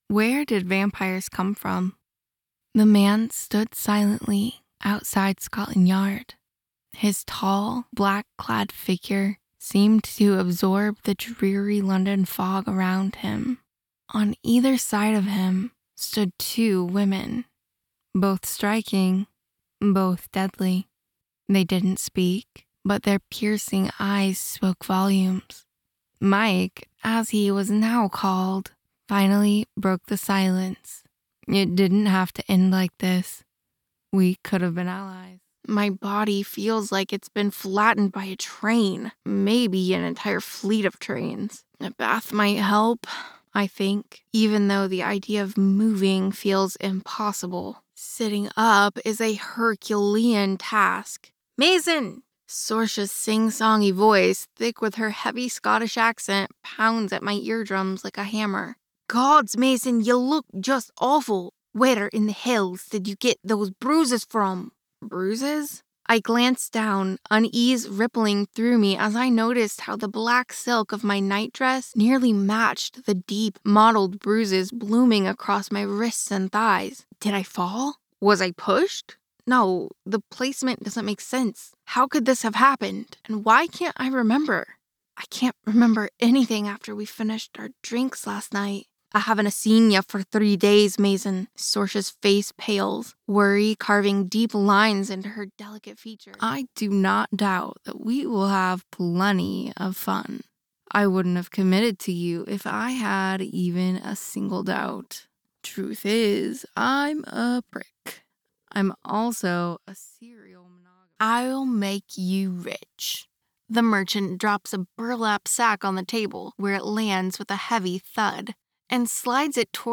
audiobook, fiction, fantasy, thriller, romance, sensual, quirky, believable, young adult, scifi, non fiction,
Full-time female American voice actor with soft